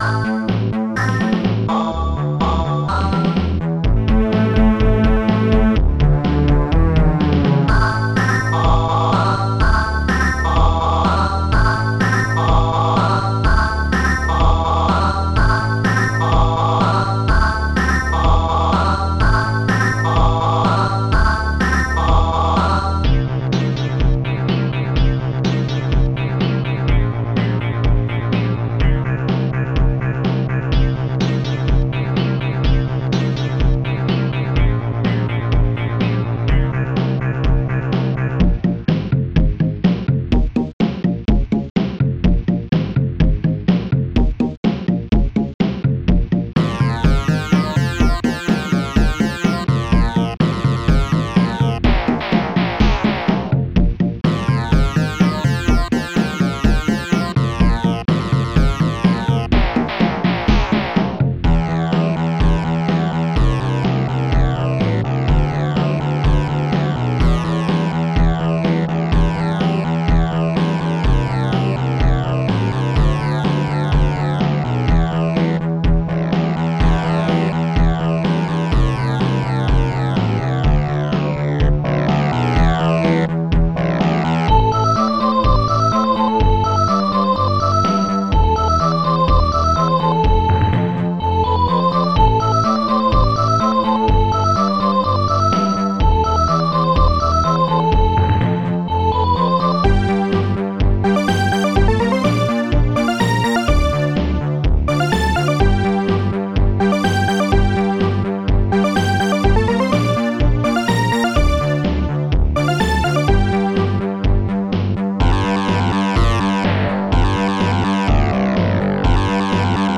Protracker Module  |  1987-04-22  |  170KB  |  2 channels  |  44,100 sample rate  |  7 minutes, 56 seconds
st-01:synthebass
st-01:snare2
st-02:bassdrum5
st-02:ahhvox
st-01:strings1
st-01:heavysynth
st-02:minimoog
st-01:epiano
st-01:hallbrass